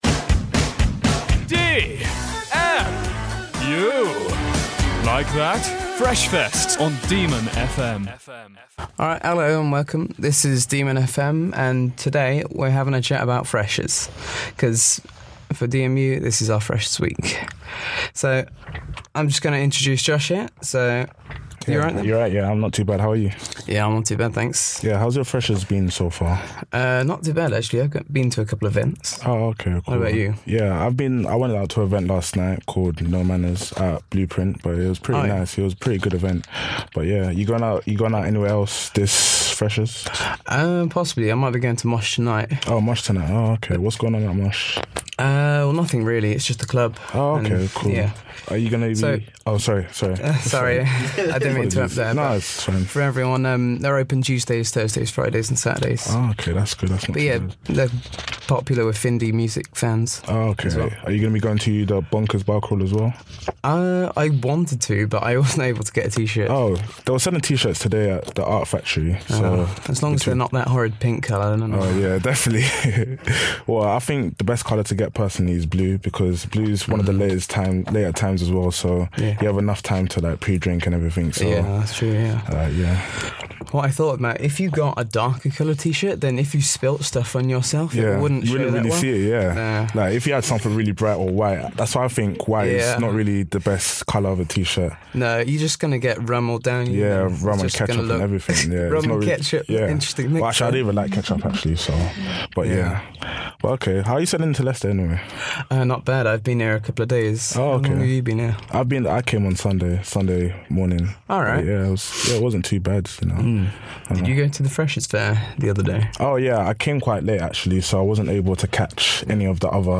Fresher Mock Show 9 (15:00 DemonFM Studio 3)
As part of Demon Media’s Open Day, DemonFM gave freshers and students at De Montfort University the chance to make their own mock radio shows to see what joining DemonFM could be like. They were told about what DemonFM is and given relatively little training but managed to pull off some epic shows.